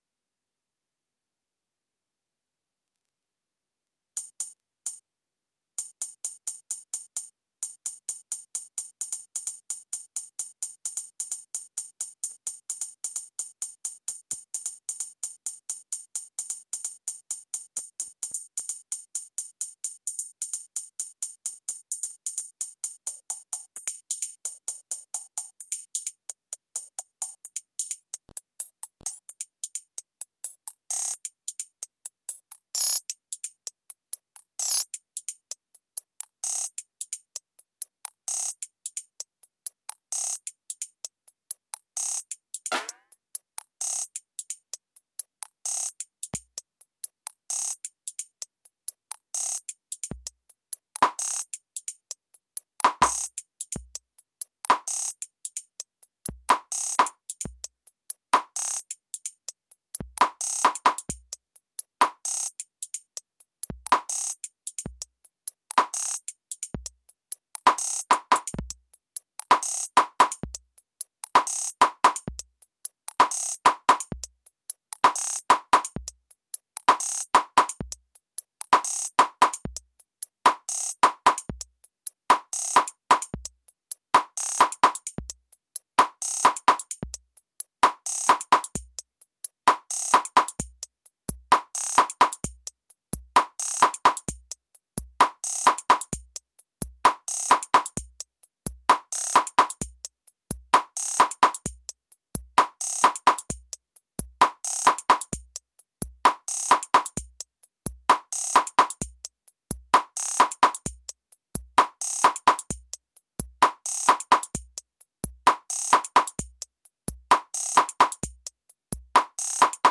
The main problem was that it took me a couple of minutes to settle into a groove which isn’t ideal from the point of view of hooking people in who might be thinking about popping to the bar. I’m not entirely sure how to deal with this in the framework of improvising everything.